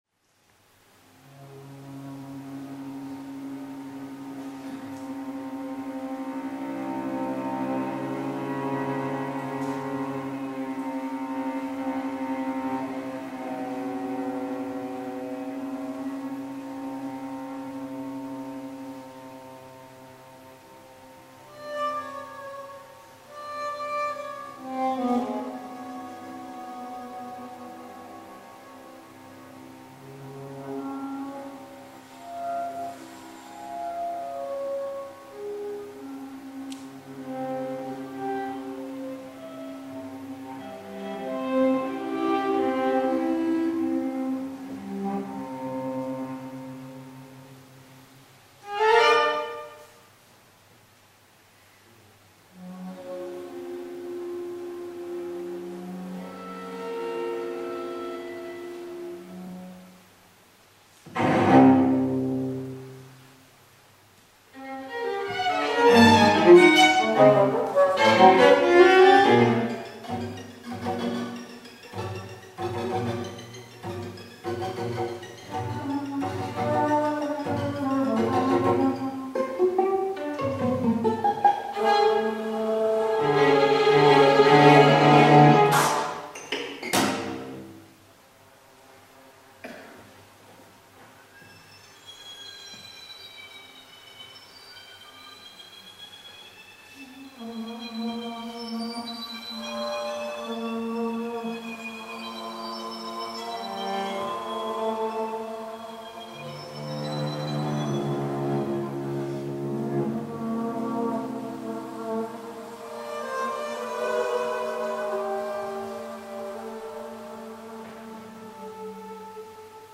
Serenade für Flôte und Streichtrio